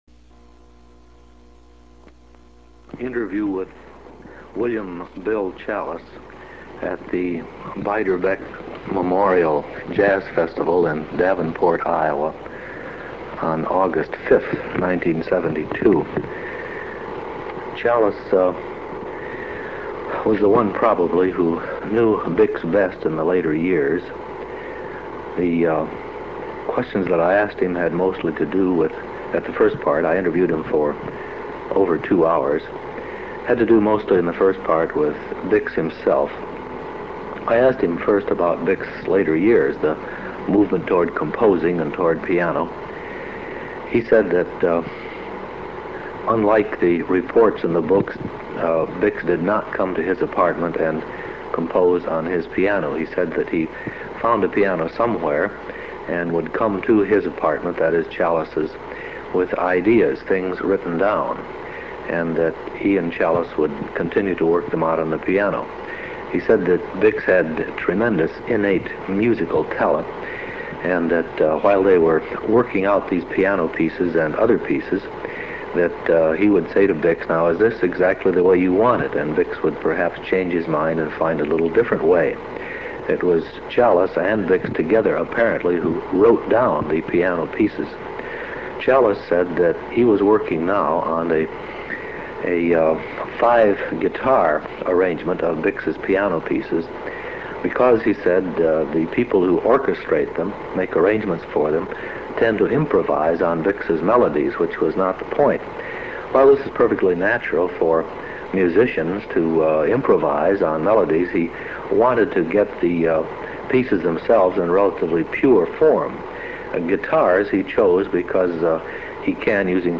Audio Tapes – Interviews of People Who Knew Bix
Audio Tapes – Interviews of People Who Knew Bix The Michigan State University Voice Library possesses a number of sound tape reels of interviews of individuals who knew Bix Beiderbecke personally. In the interviews, the various individuals talk about their personal knowledge of Bix and relate their experiences and/ or some anecdote of interest.